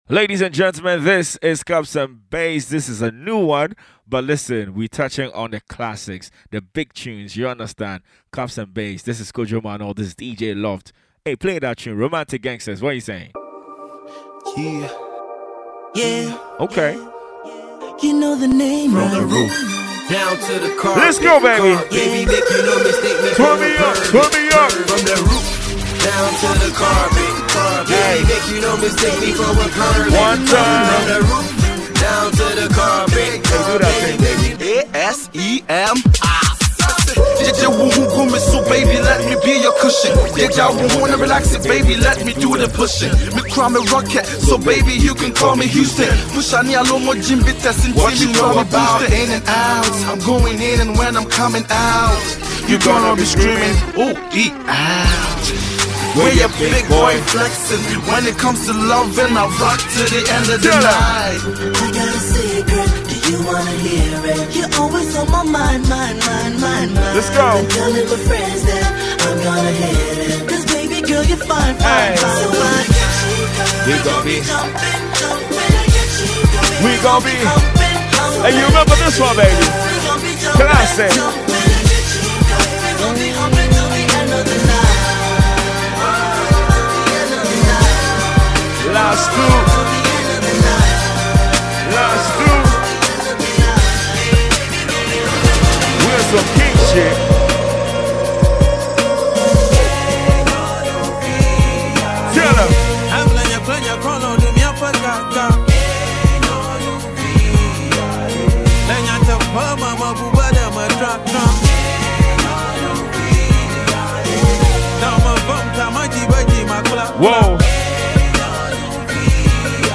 Enjoy this free mp3 download mixtape.